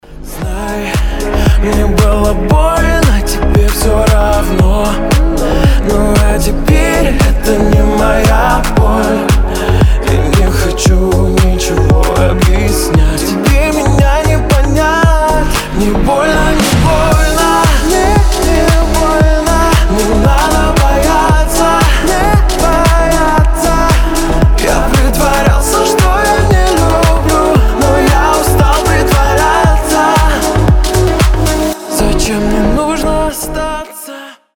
мужской голос